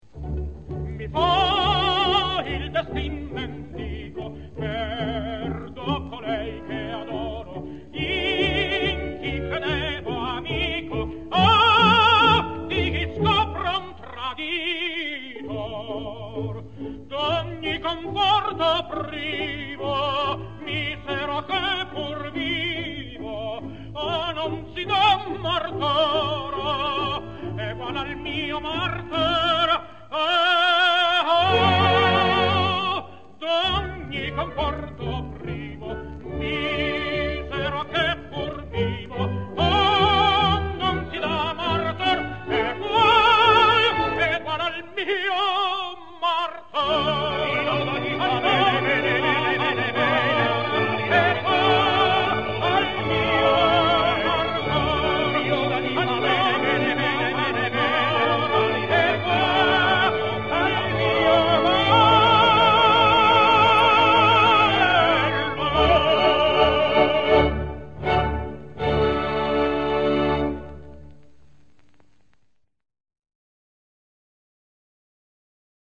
registrazione dal vivo.